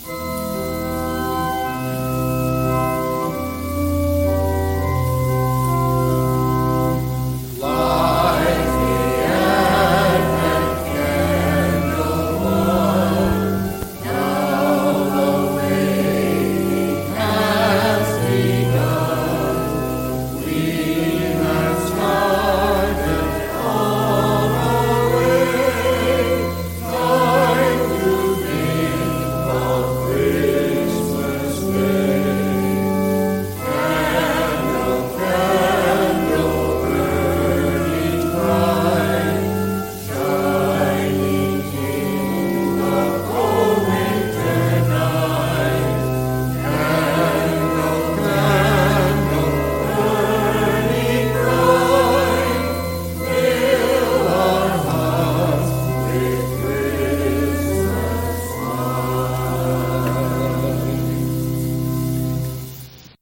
Singing and lighting the advent candles come in stages counting from first Sunday of Advent to Christmas eve.